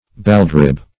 Search Result for " baldrib" : The Collaborative International Dictionary of English v.0.48: Baldrib \Bald"rib`\, n. A piece of pork cut lower down than the sparerib, and destitute of fat.